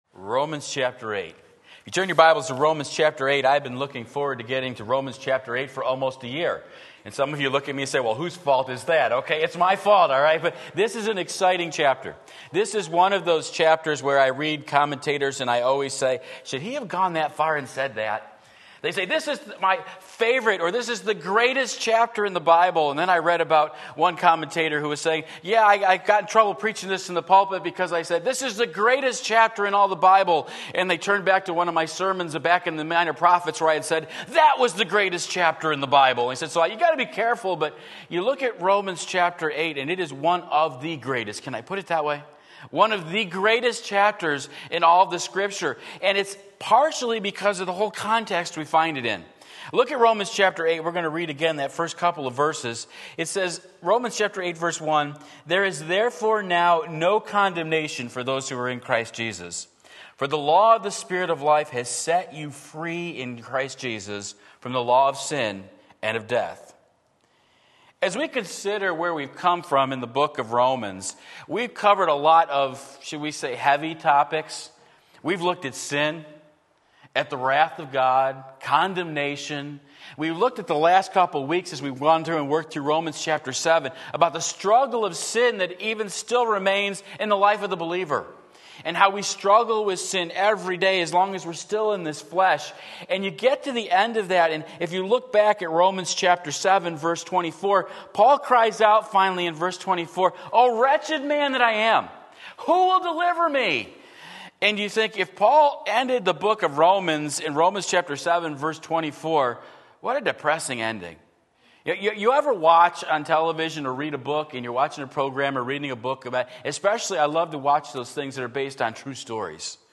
Sermon Link
Romans 8:1-4 Sunday Morning Service